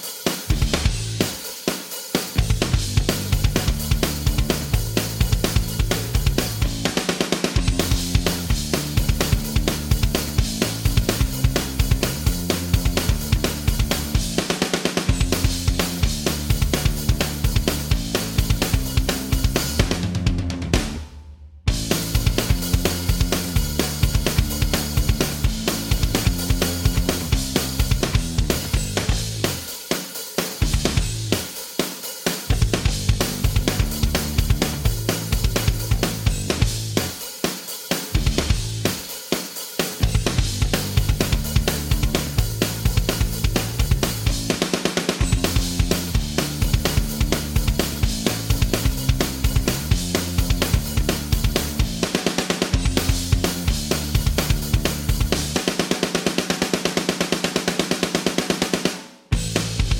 Minus Main Guitar For Guitarists 3:23 Buy £1.50